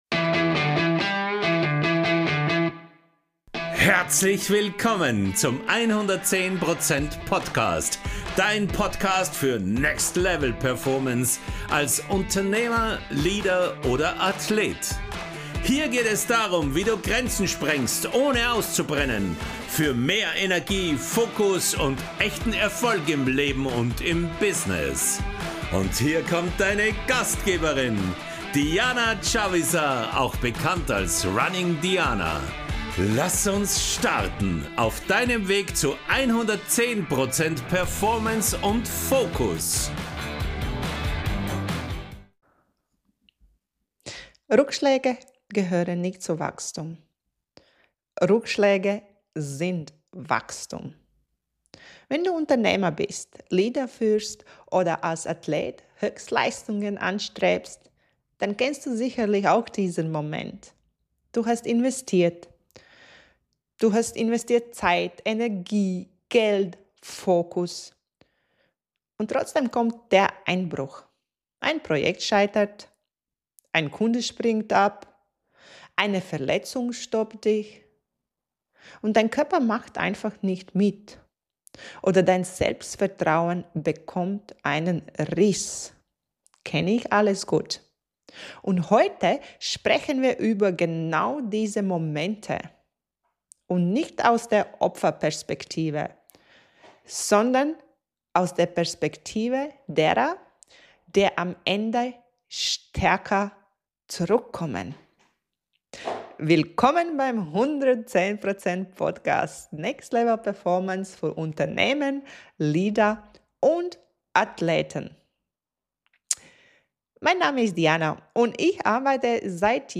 Solo-Folge